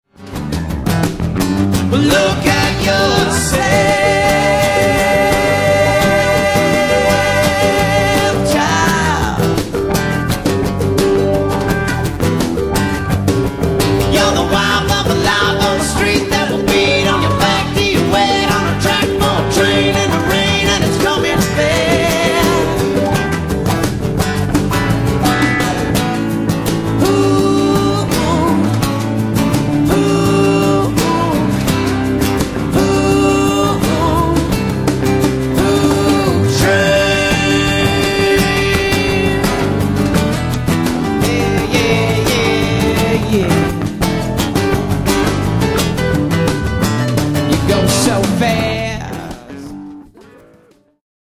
damn funky band